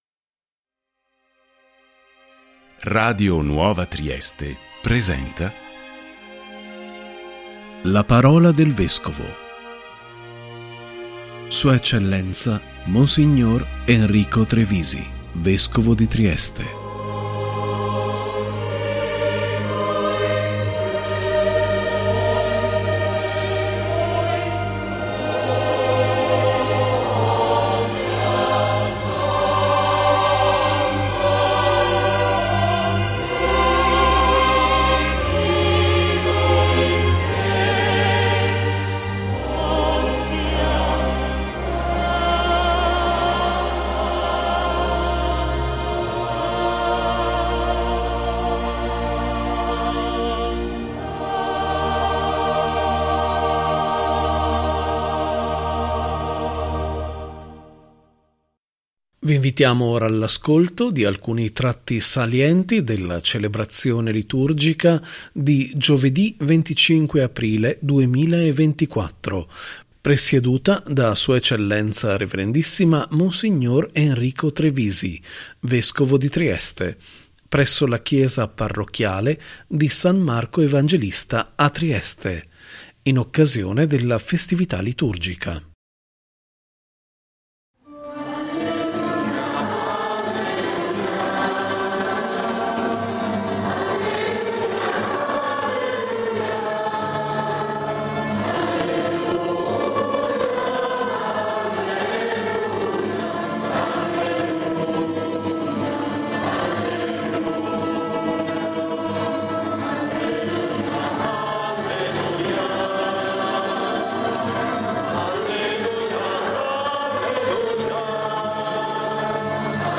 ♦ si è tenuta la celebrazione liturgica di Giovedì 25 aprile 2024 presieduta da S.E. Rev.issima Mons. Enrico Trevisi, Vescovo di Trieste presso la chiesa parrocchiale di San Marco Evangelista a Trieste in occasione della Festività liturgica.